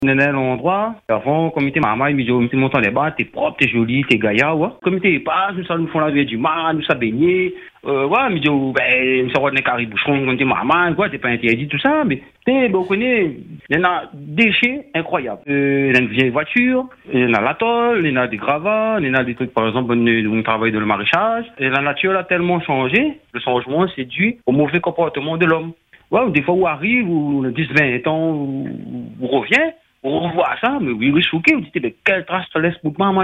À travers son témoignage, il exprime surtout une tristesse profonde et un sentiment d’impuissance face à cette dégradation progressive de l’environnement.